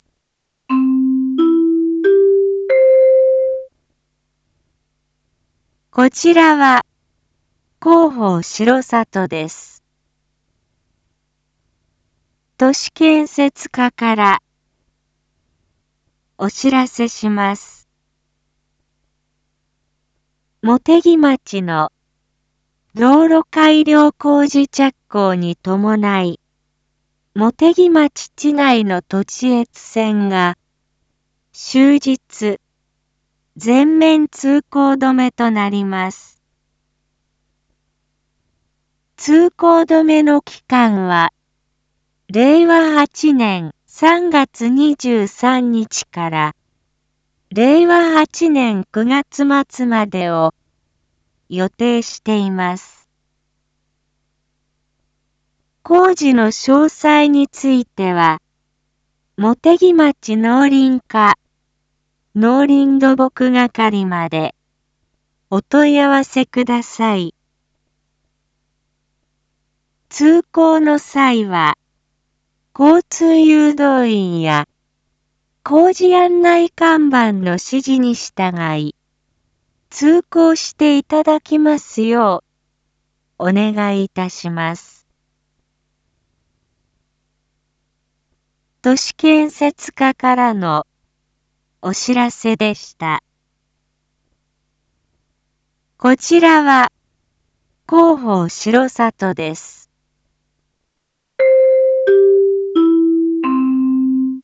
Back Home 一般放送情報 音声放送 再生 一般放送情報 登録日時：2026-03-22 19:01:46 タイトル：茂木町林道土地越線道路改良工事③ インフォメーション：こちらは広報しろさとです。